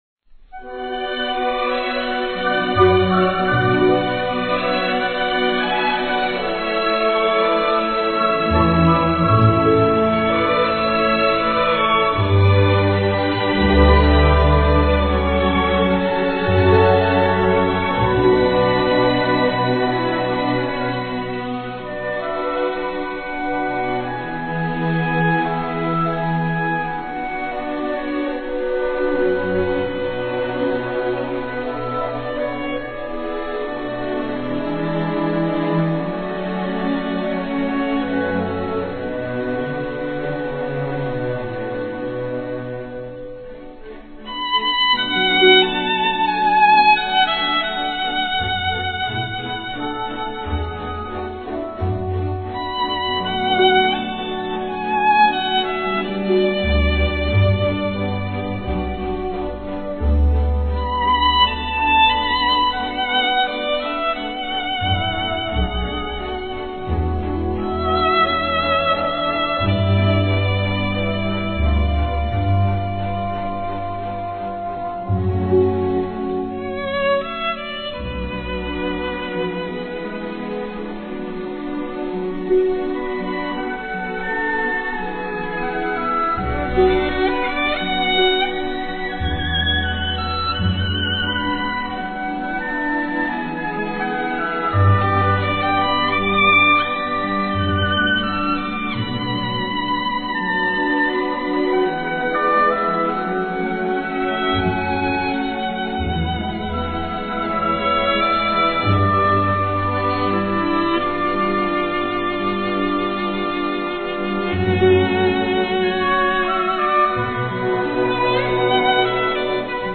(小提琴)